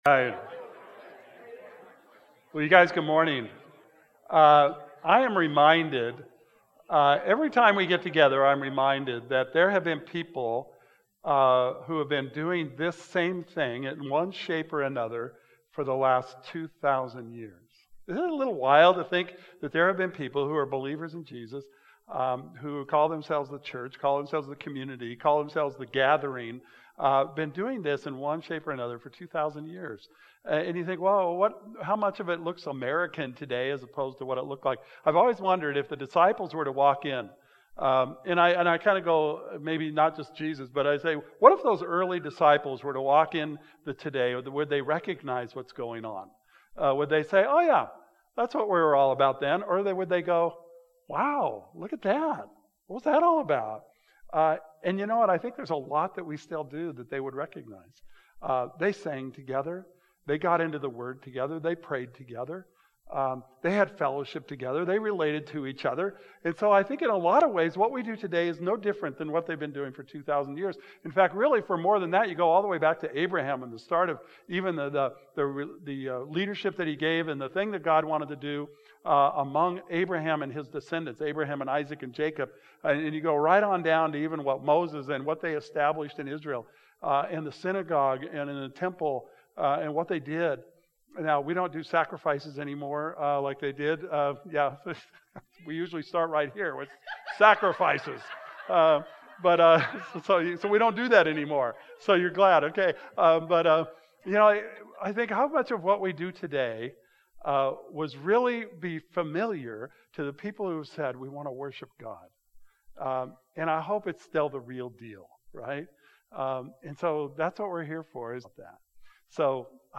It's a series focused on discipleship and what it truly looks like to follow Jesus. Due to some technical issues outside of our control, the video message didn't end up working, so please enjoy the audio recording of the message.